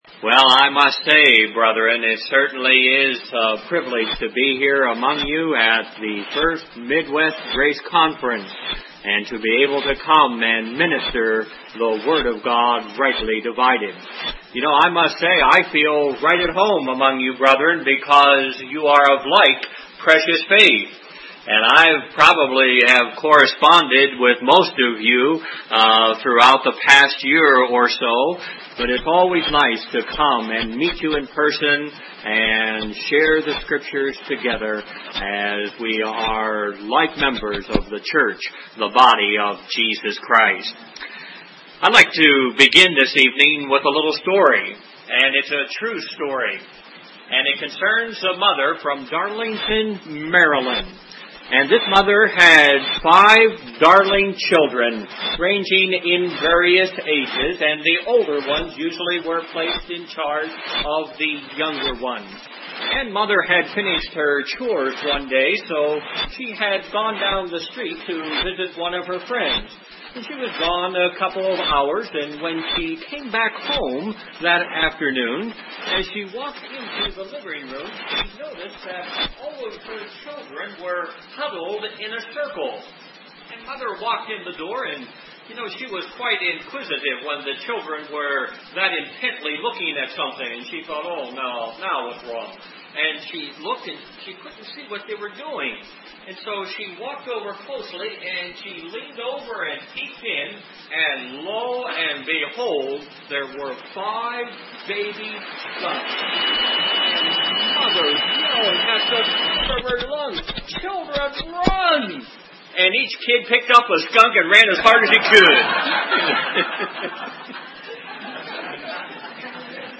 A message from the 1989 Spring Bible Conference of the Midwest Grace Fellowship.